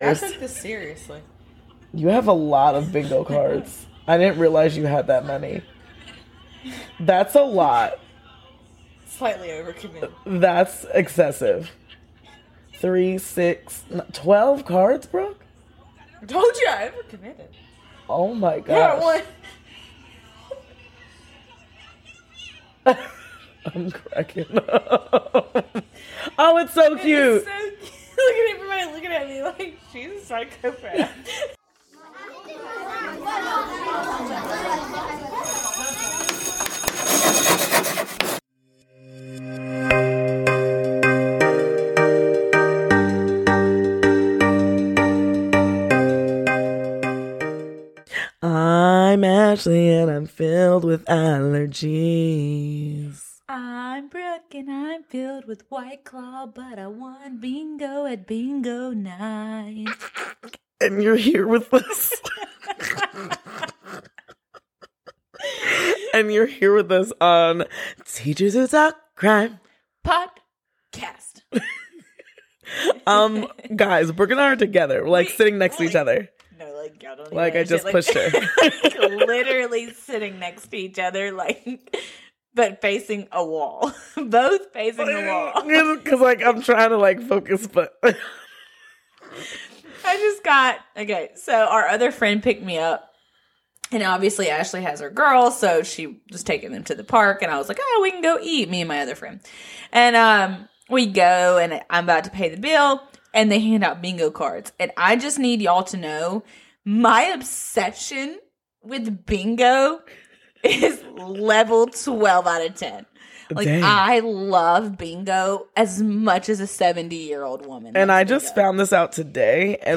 Bare with us and enjoy our new sound quality. Brought to you by our newest microphones! :)